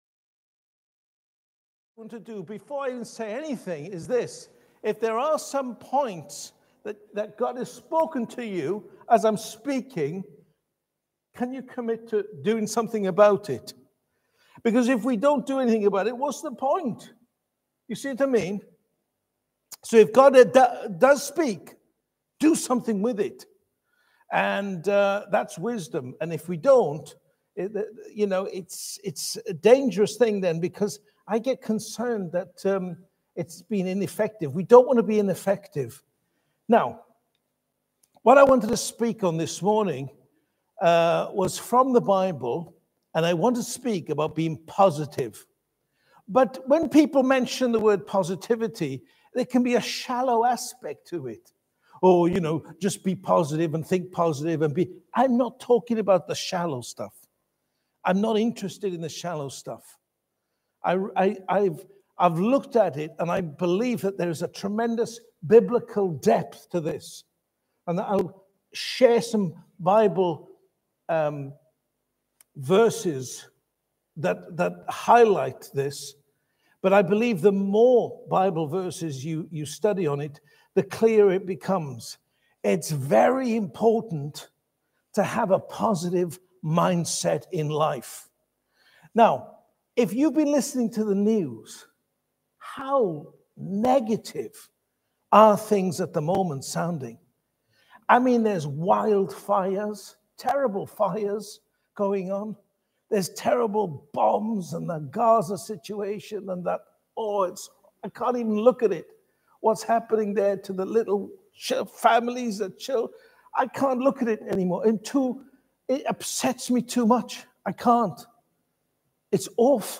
Audio and video teachings from Living Faith Church